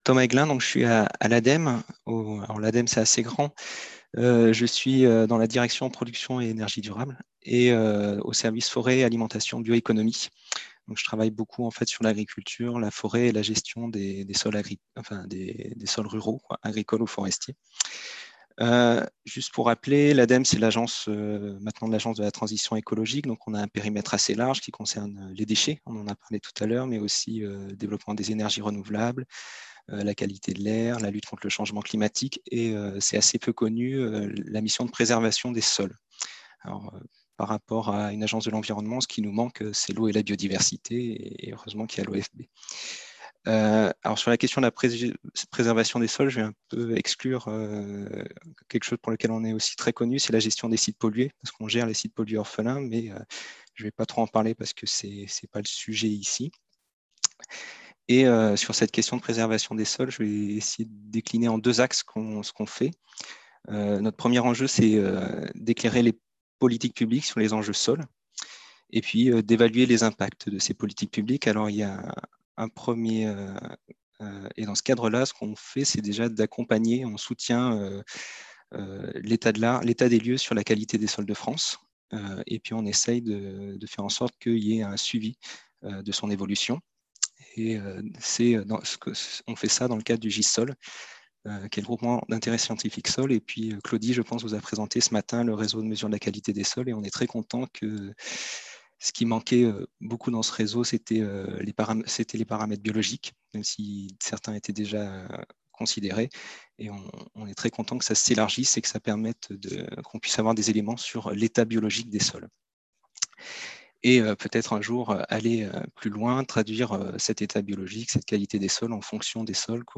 Débat sur les enjeux de la connaissance de la biodiversité des sols. Quels leviers ?